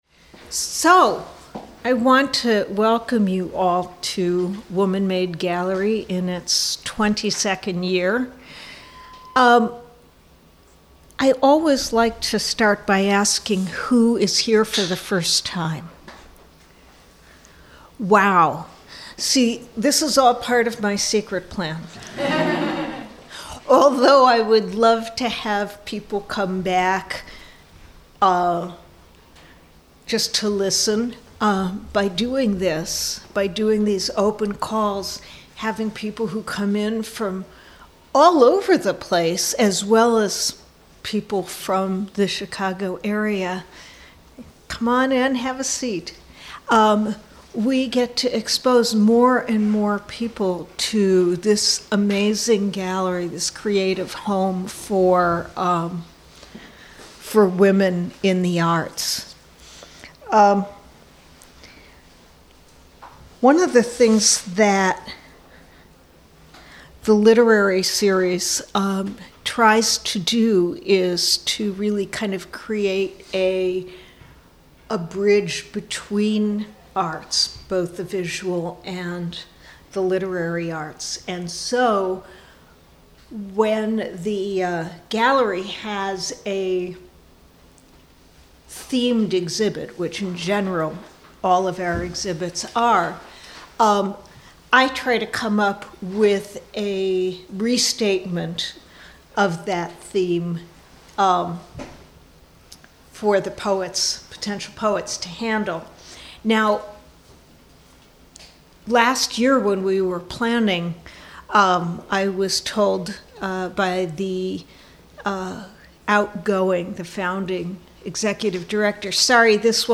Woman Made Gallery Poetry Reading Series recorded Sunday, 2014-12-06, 1:30 - 3:30 p.m.